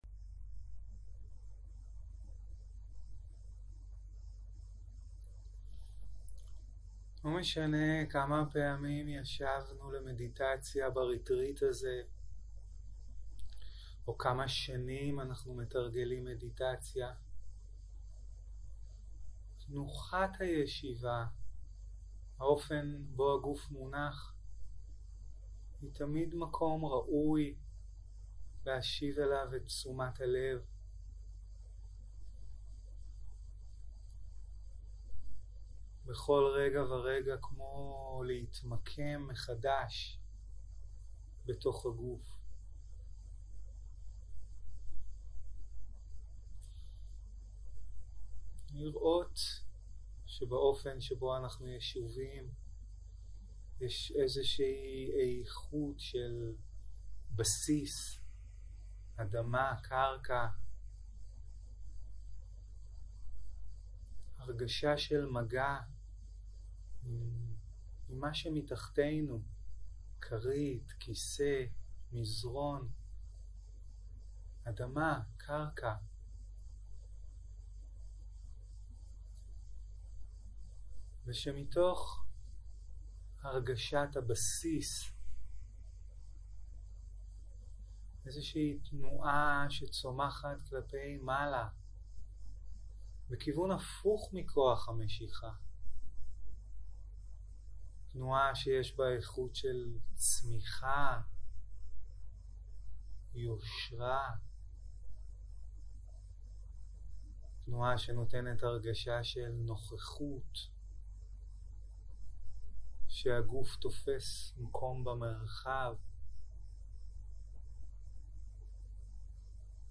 יום 2 - צהרים - מדיטציה מונחית - קשב לגוף ולנשימה - הקלטה 3 0:00 0:00 סוג ההקלטה: Dharma type: Guided meditation שפת ההקלטה: Dharma talk language: Hebrew